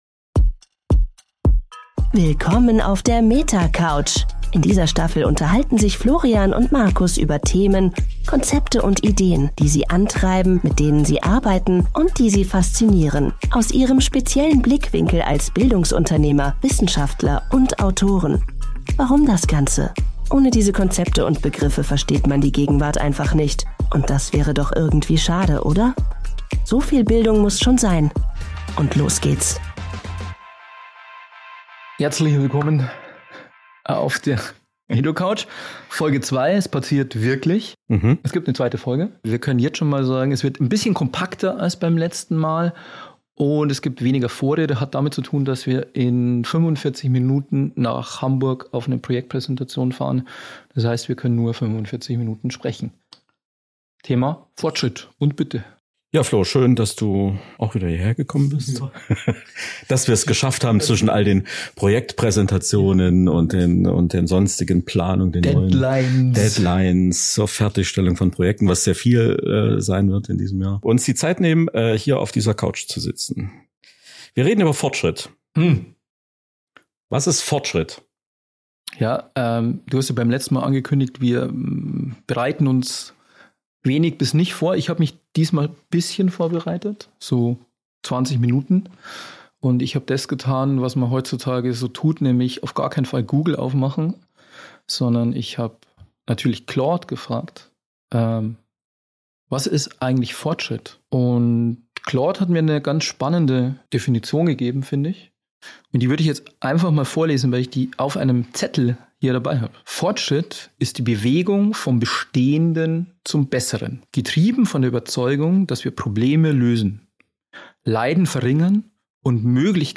Und kann Fortschritt die Demokratie besiegen? Ein Gespräch über Dampfmaschinen und Algorithmen, über China und Deutschland, über das Rollen der Maschine – und die Frage, was wir hier eigentlich tun.